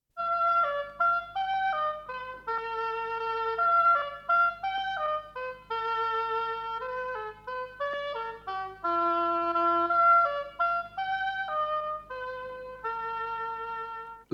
sintonia